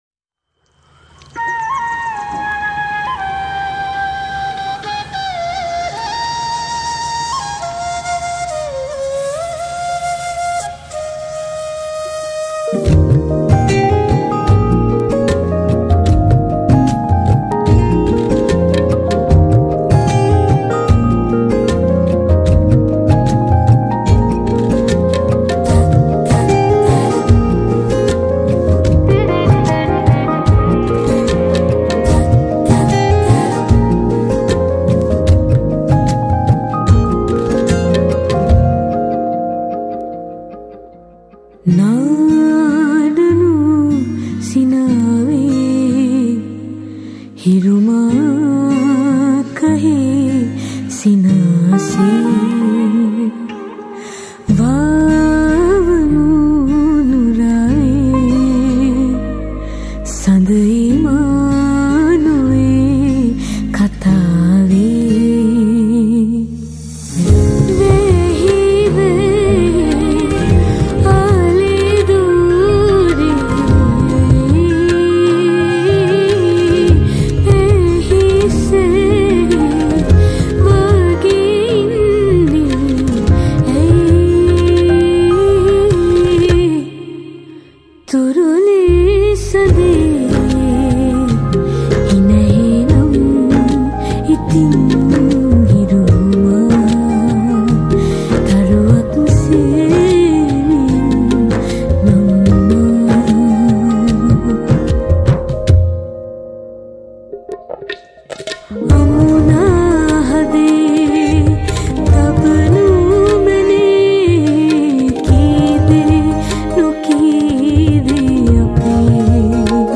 Keys
Flute
Percussion
Bass
Acoustic Guitar
Electric Guitar